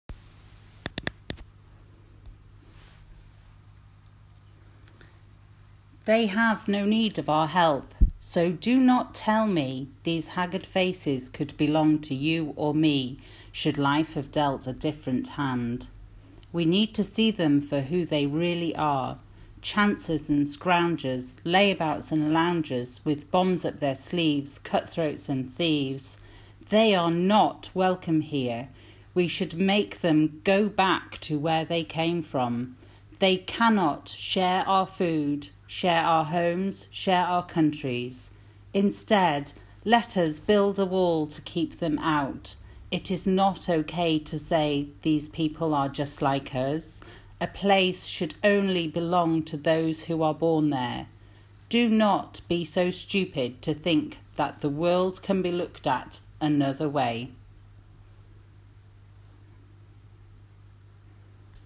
Male Voice Forward
refugee-female-forward.m4a